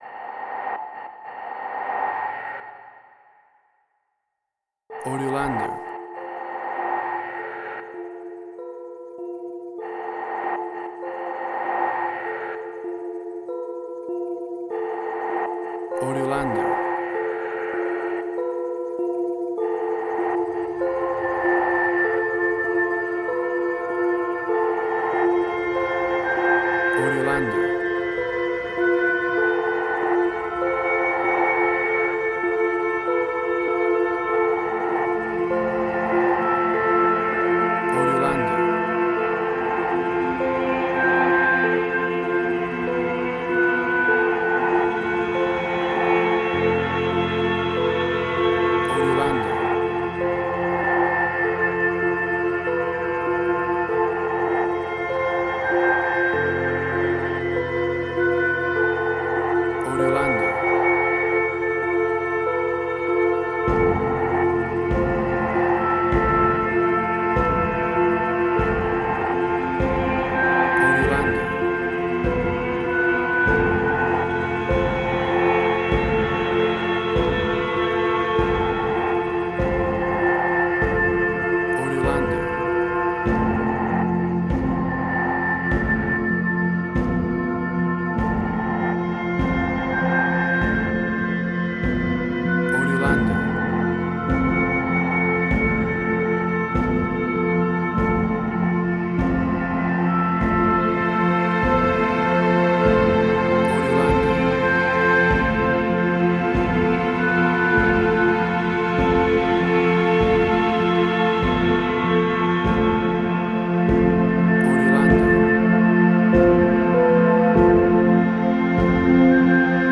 Ambient
Tempo (BPM): 50